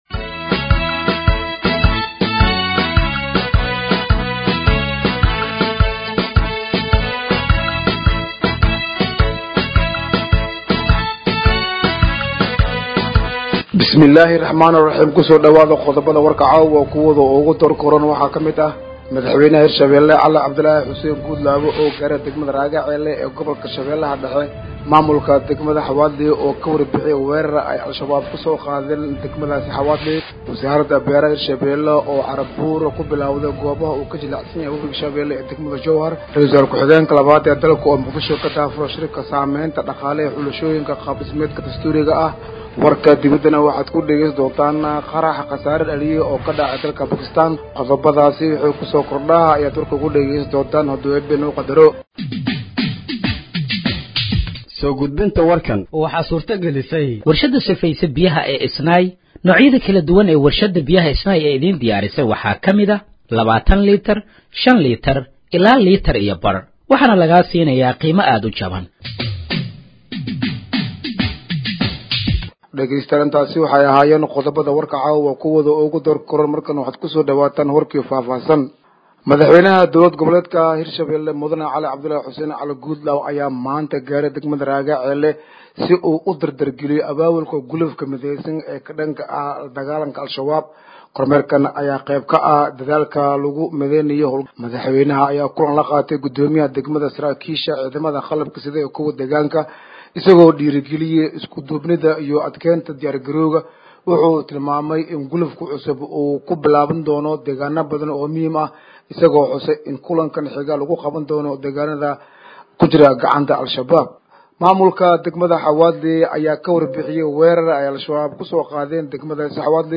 Dhageeyso Warka Habeenimo ee Radiojowhar 21/05/2025